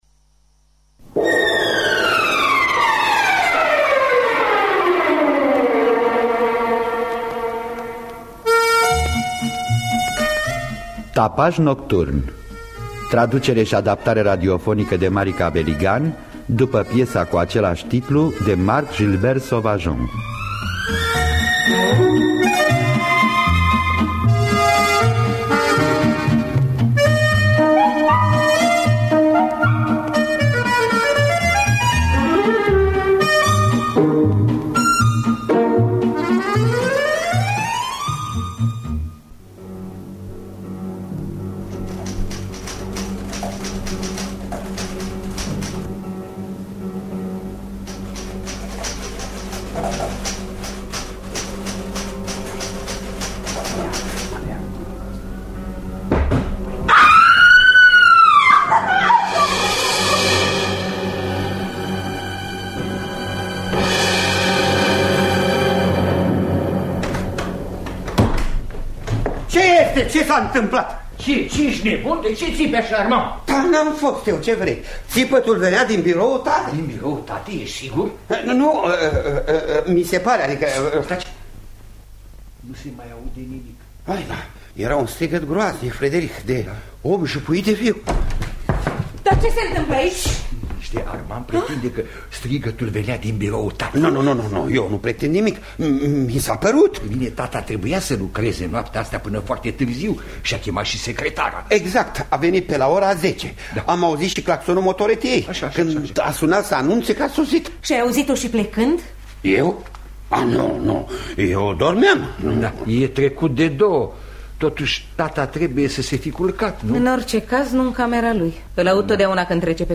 Traducerea şi adaptarea radiofonică